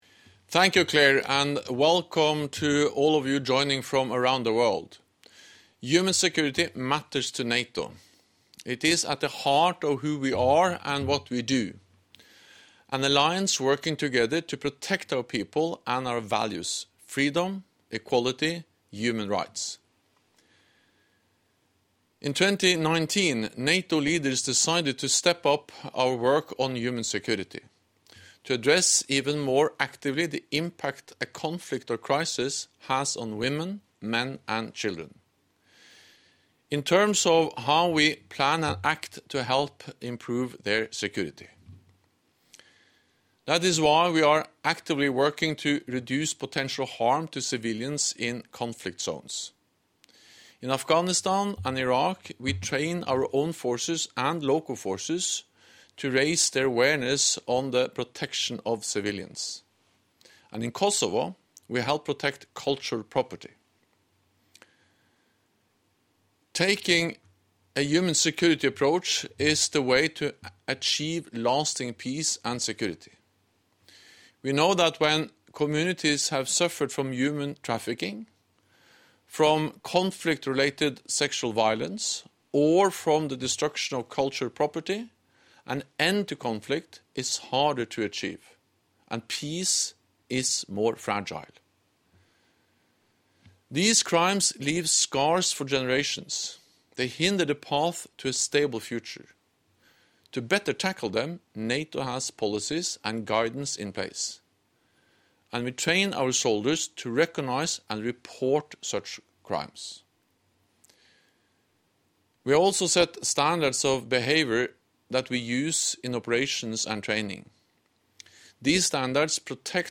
''A changing approach to security'' - Remarks by NATO Secretary General Jens Stoltenberg at the conference on Human Security hosted by NATO
(As delivered)